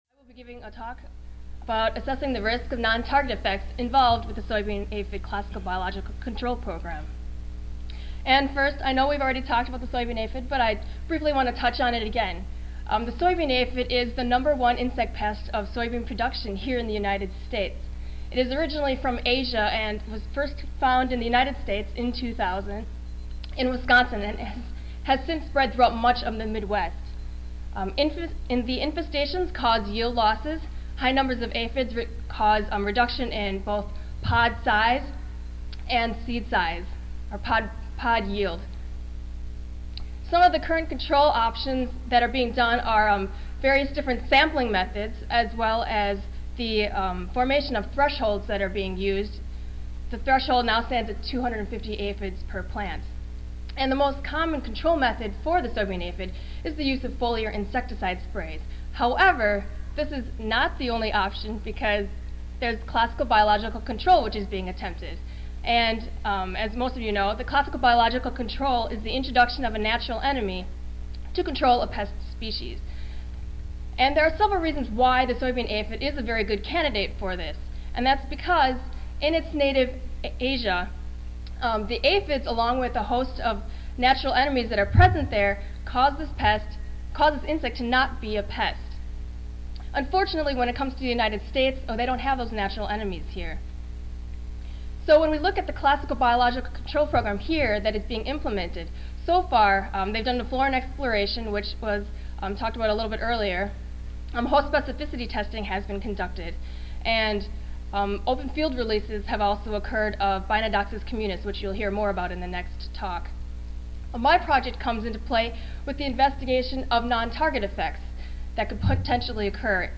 Recorded presentation